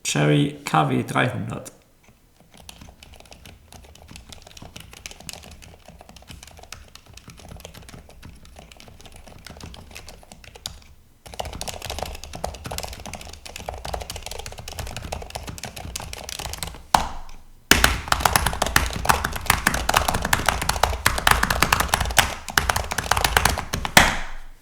Durch Gummipuffer am Stempel arbeiten die Silent-Taster außerdem leiser.
Die KW 300 arbeitet leise und weitgehend funktional.
Akustik: Ruhe-Qualitäten
Ein solcher zeitgemäßer Aufbau sorgt zwar nicht für vollständig satte Anschläge, aber er beruhigt die Klangkulisse spürbar und generiert mit Silent-Tastern einen recht dumpfen Klang.